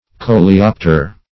coleopter - definition of coleopter - synonyms, pronunciation, spelling from Free Dictionary Search Result for " coleopter" : The Collaborative International Dictionary of English v.0.48: Coleopter \Co`le*op"ter\, n. (Zool.) One of the Coleoptera.